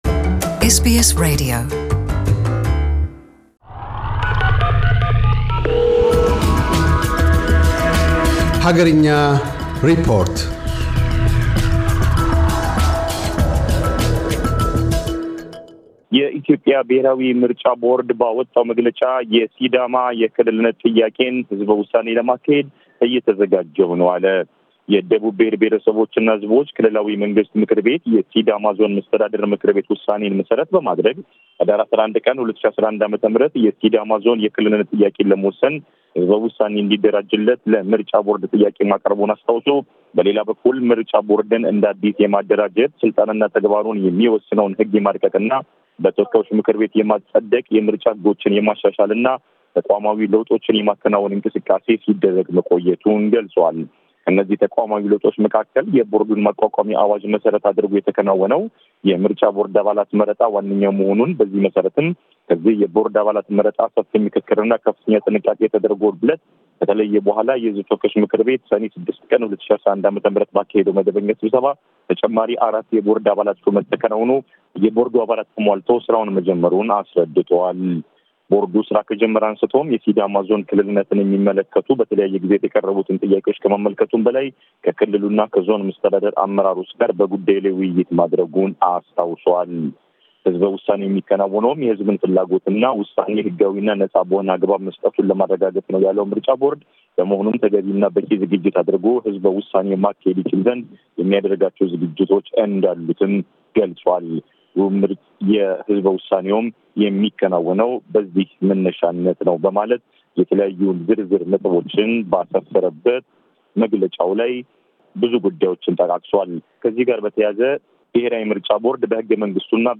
አገርኛ ሪፖርት - የኢትዮጵያ ብሔራዊ የምርጫ ቦርድ የሲዳማን የክልልነት ጥያቄ በሕዝበ ውሳኔ ለማስወሰን በመሰናዶ ላይ መሆኑን ቀዳሚ ትኩረቱ አድርጓል።